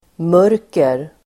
Uttal: [m'ör:ker]